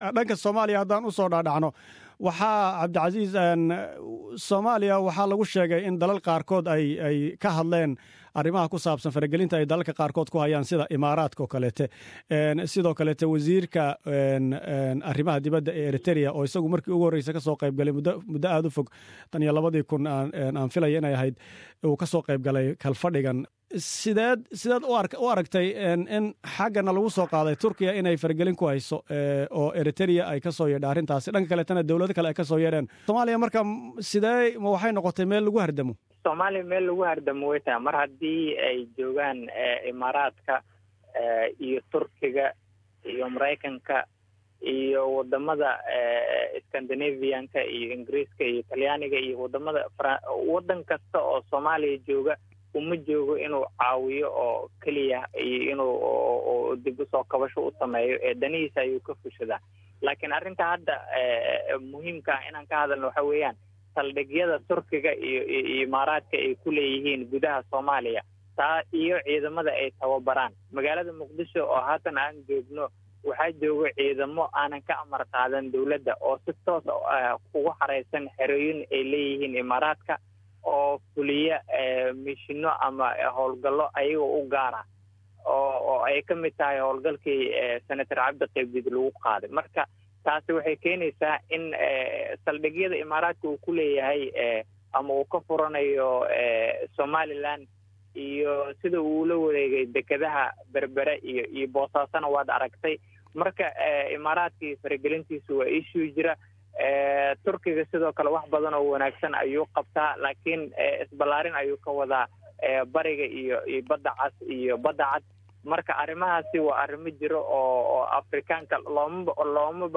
Waraysi saamaynta faragalinta arimaha Soomaaliya.